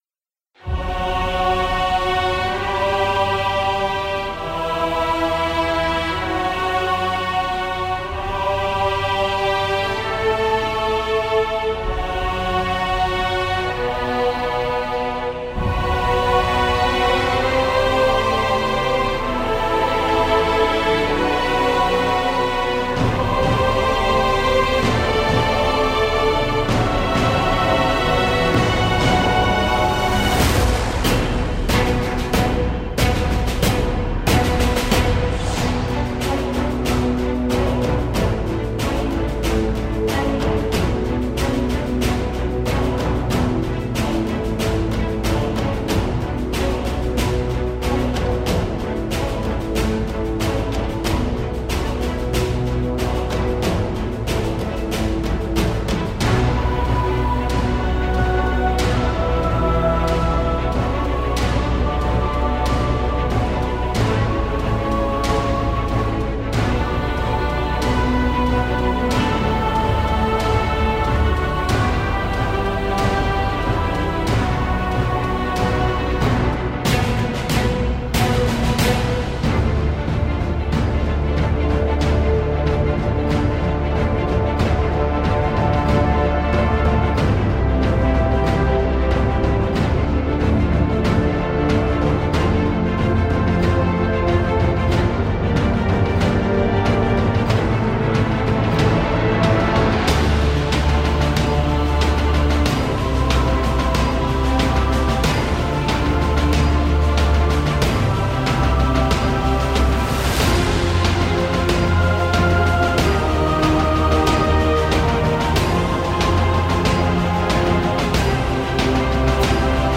Epic orchestral track for trailers and RPG.